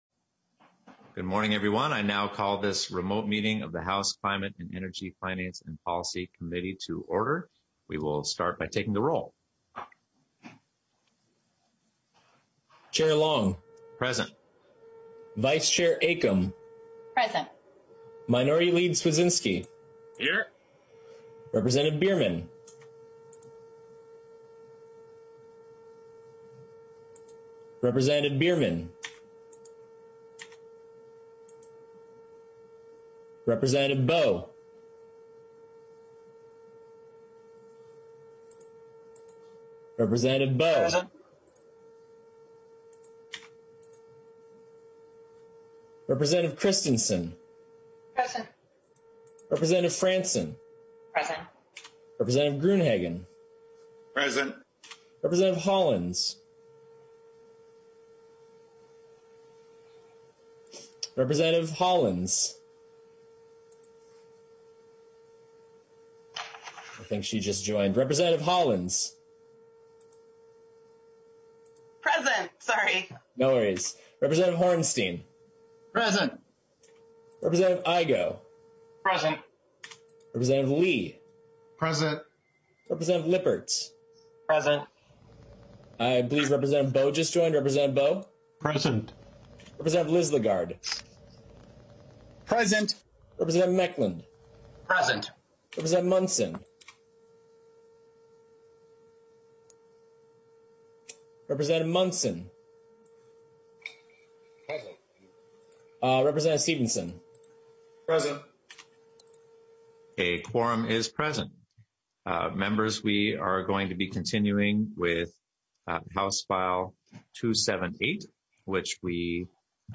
This remote hearing is taking place pursuant to Rule 10.01.